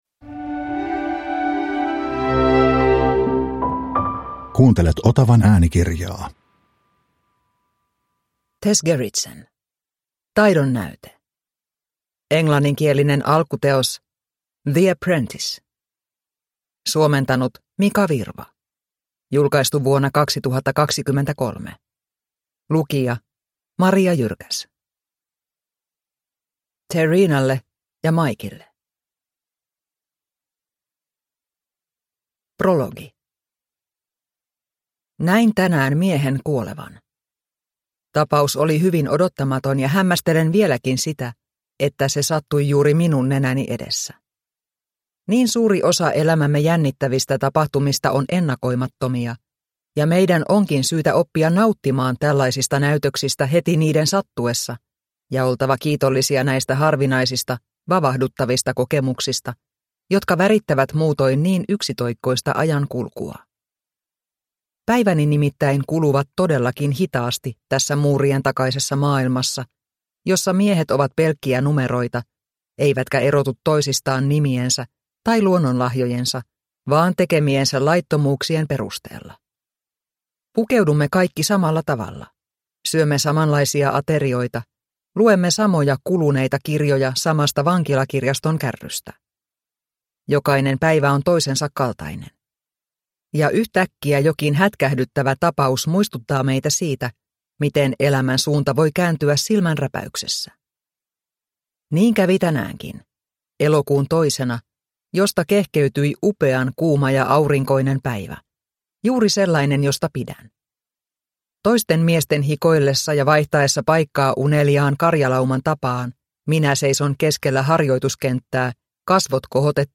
Taidonnäyte – Ljudbok – Laddas ner